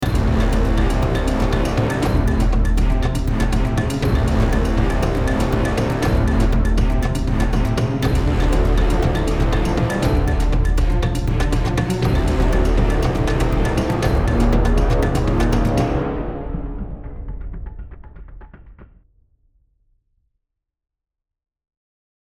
Therefore, I’ll reinforce the drums with some compression on the big low bass – heavy hits, and also add some parallel compression with a touch of distortion to add punch, warmth and presence. I’ll also put reverb and some delays onto the hits to give a more interesting dynamic, leaving the cue sounding like this:
Sequence with processing.